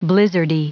Prononciation du mot blizzardy en anglais (fichier audio)
Prononciation du mot : blizzardy